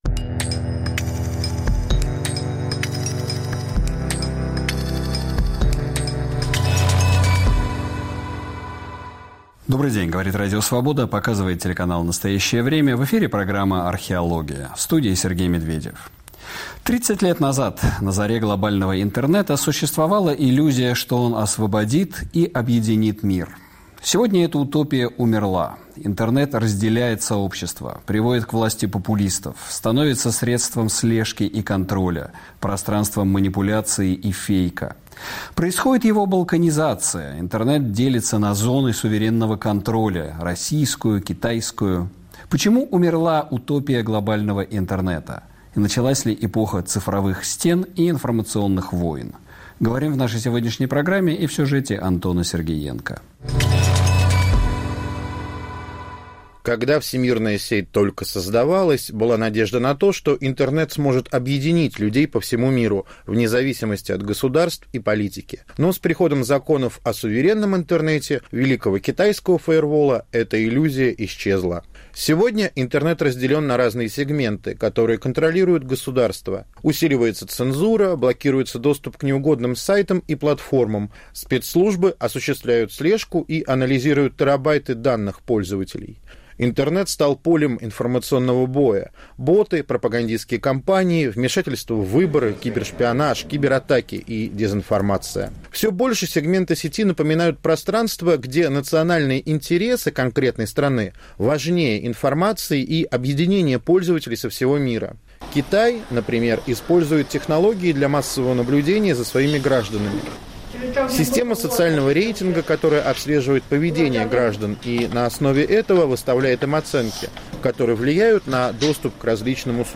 Повтор эфира от 12 июня 2024 года.